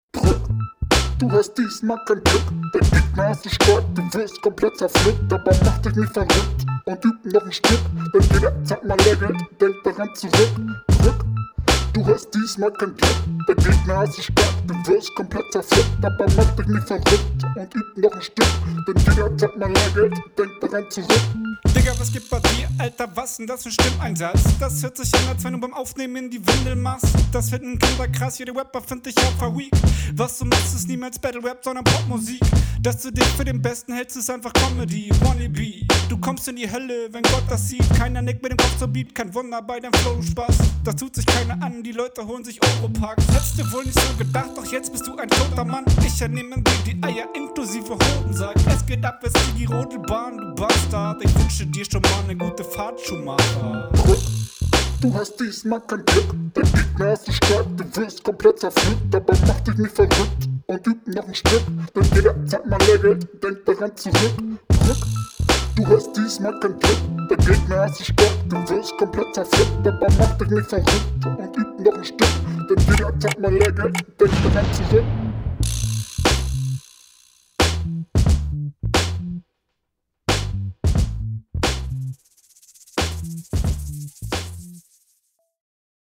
der stimmfilter kommt lustig. aufnahmequali diesmal klar besser. flow auch wieder gut. text ist ok …
Flowst cool darauf, hier kommt dein Stakkato Flow mehr zur Geltung.